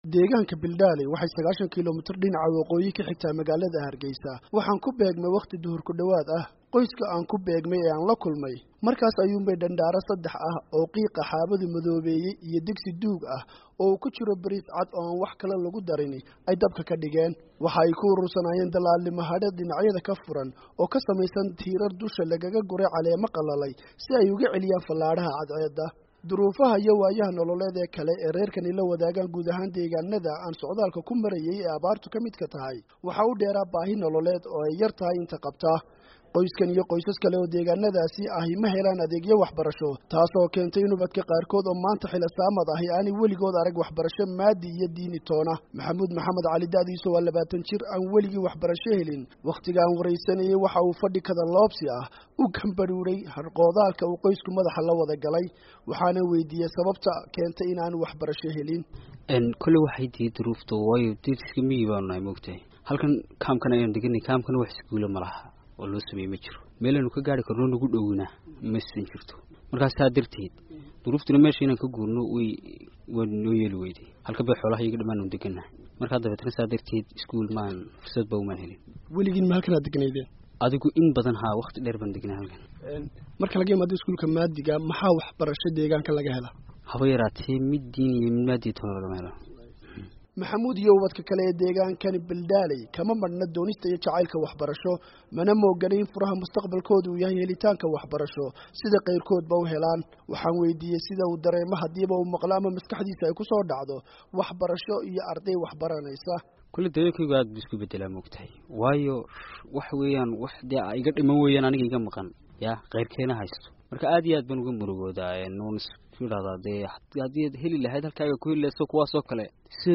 Warbixin: Xaaladda Nololeed ee Dadka Reer Miyiga Somaliland